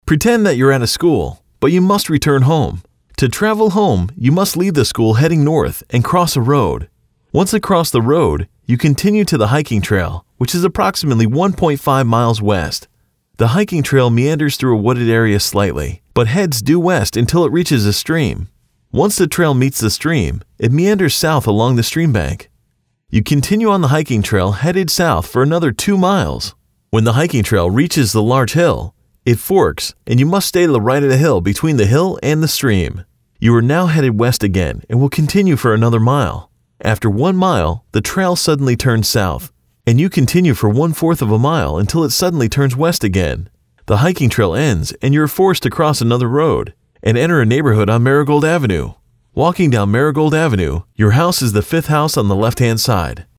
recorded directions and try to sketch a simple (but accurate) map as you follow along.